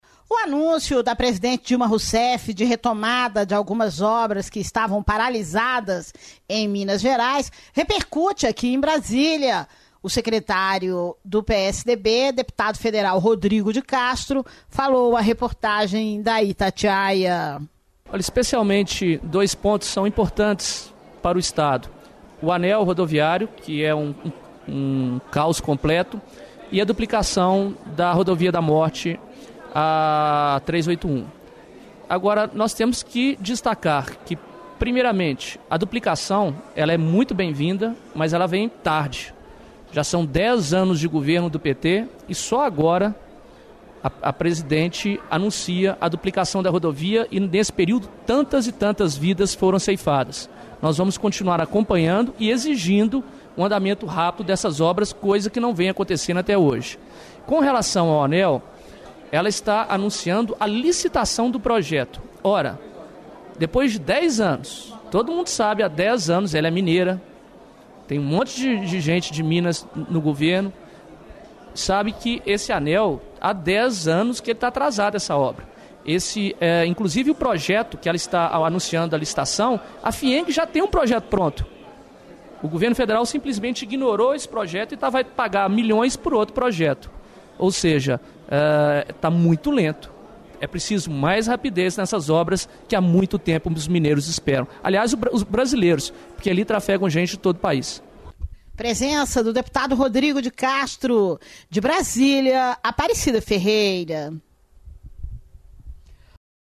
Rodrigo de Castro fala à reportagem da Itatiaia sobre retomada de algumas obras do governo federal que estavam paralizadas em Minas